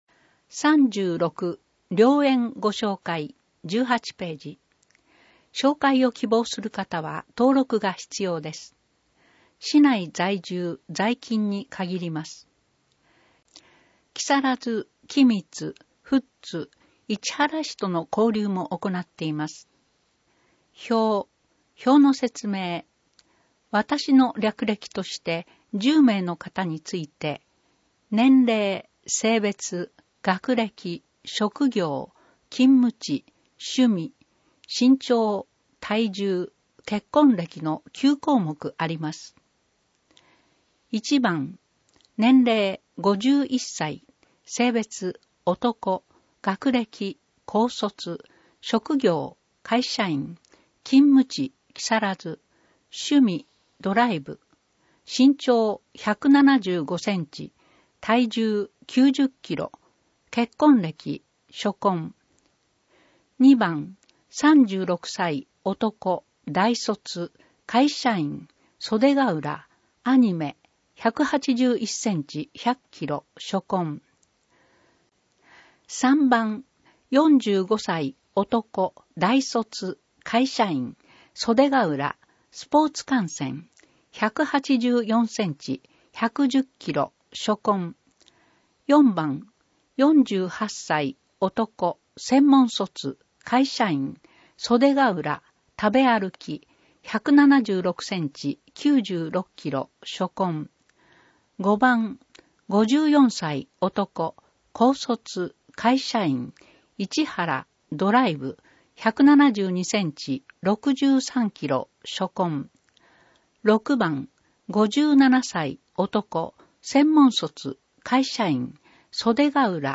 目の不自由な人などのために録音されたデイジー図書を掲載しています。